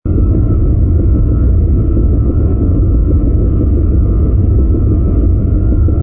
rumble_transport.wav